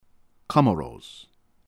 View pronunciation guides for names in the news from Comoros.
AH -mehd ahb-dah- LAH ahb-dehr-reh- MAHN